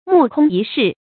目空一世 注音： ㄇㄨˋ ㄎㄨㄙ ㄧ ㄕㄧˋ 讀音讀法： 意思解釋： 什么都不放在眼里。